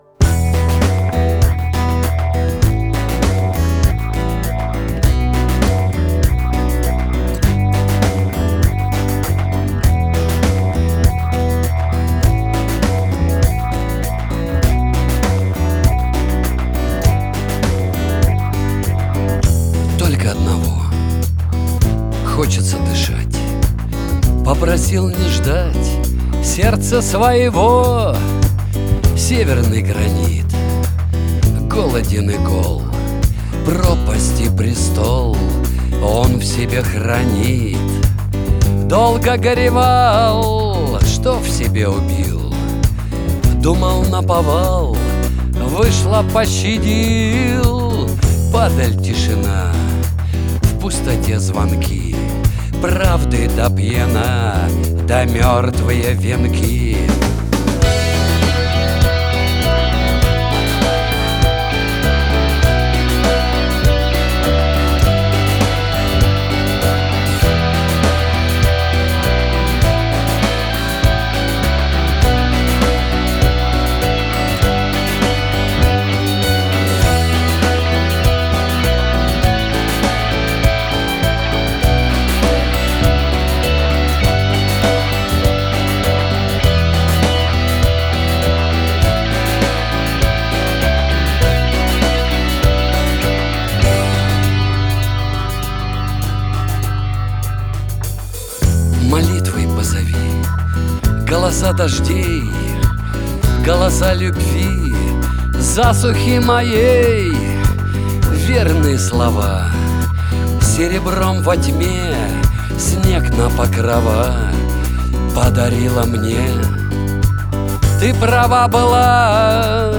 Genre: Rock